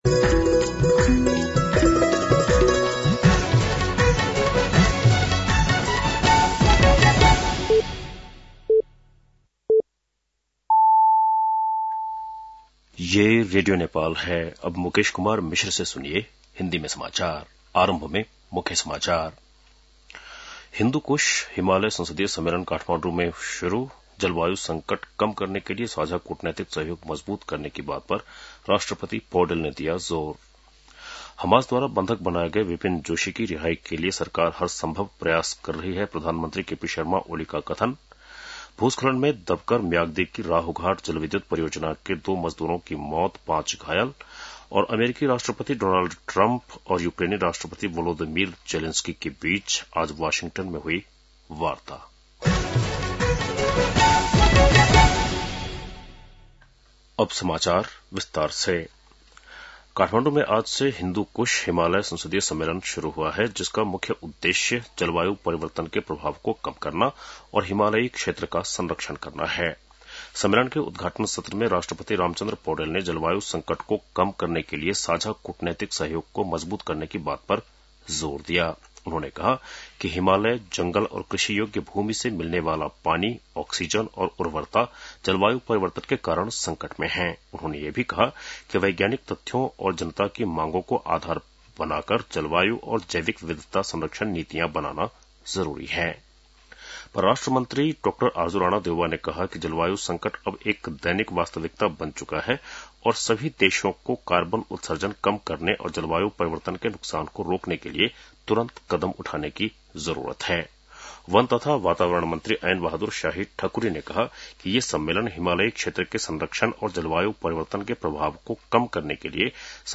बेलुकी १० बजेको हिन्दी समाचार : २ भदौ , २०८२